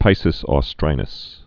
(pīsĭs ô-strīnəs)